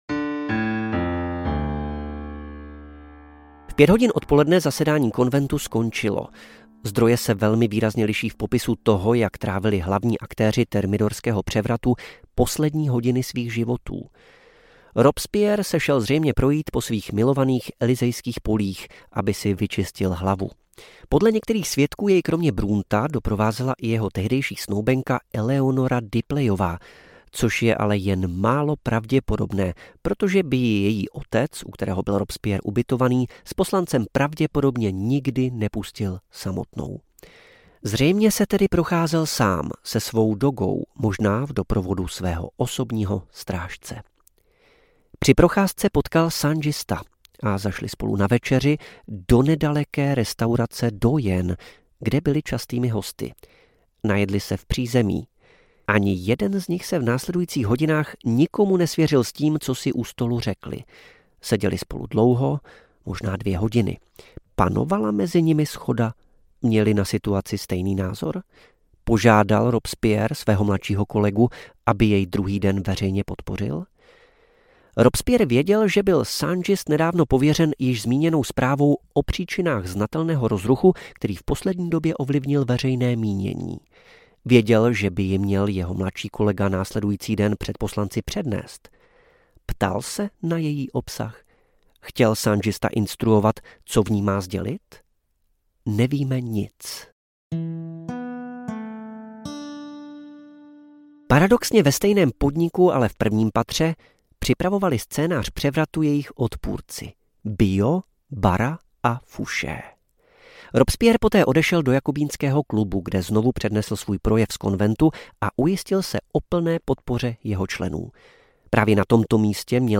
Saint-Just – krvavý démon Francouzské revoluce audiokniha
Ukázka z knihy
saint-just-krvavy-demon-francouzske-revoluce-audiokniha